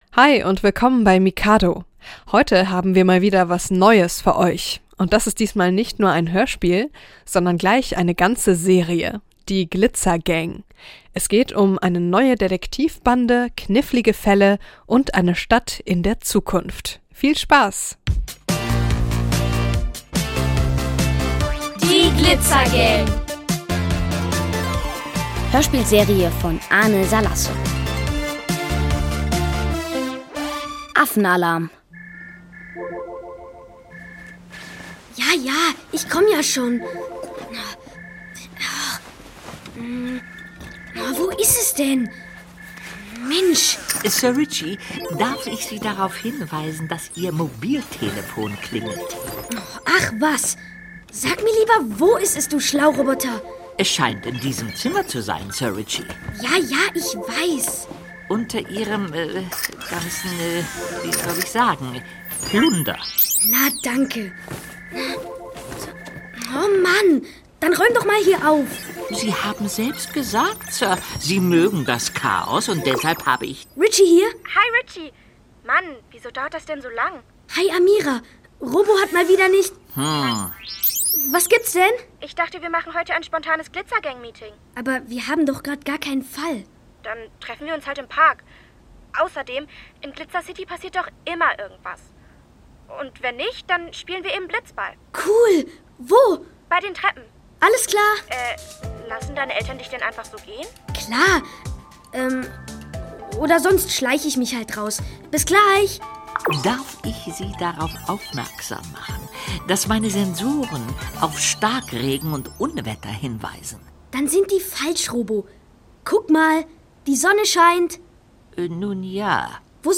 Kinderhörspiel: Die Glitzer-Gang (Folge 1+2) ~ Hörspiele, Geschichten und Märchen für Kinder | Mikado Podcast